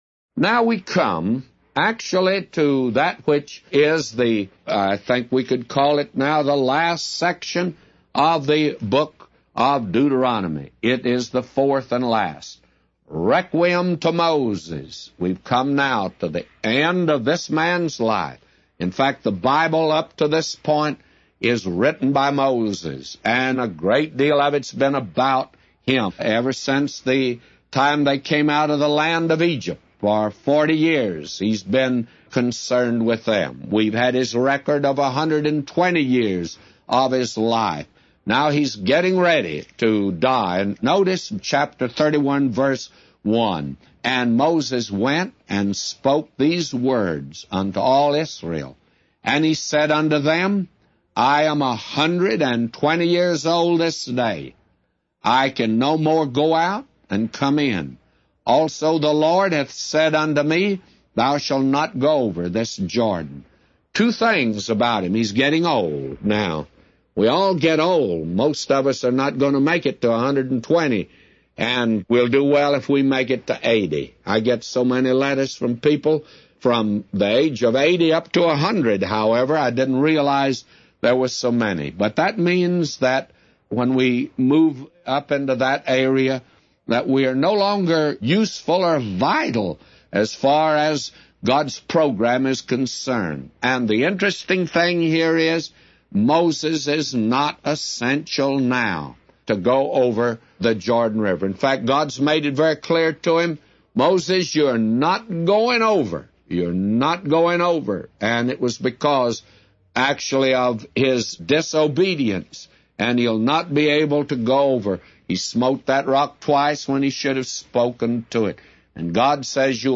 A Commentary By J Vernon MCgee For Deuteronomy 31:1-999